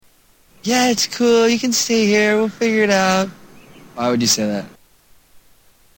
Spencer bitchily mimics Heidi